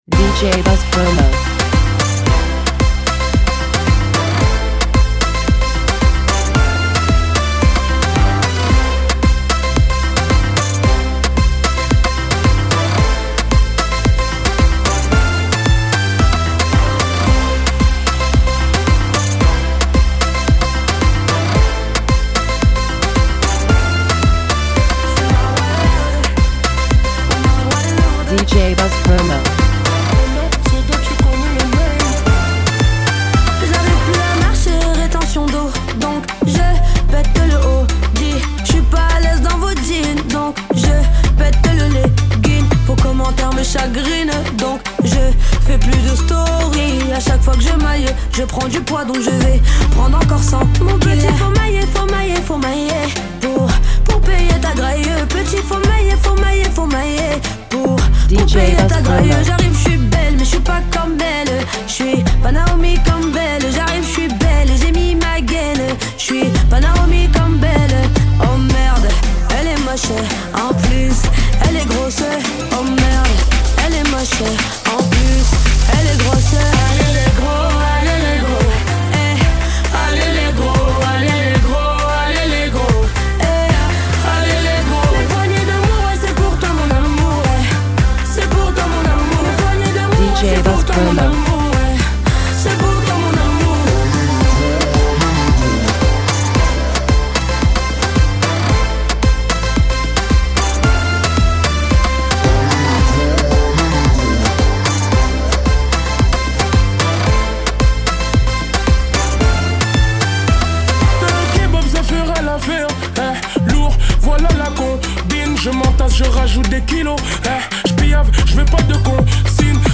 Remixes!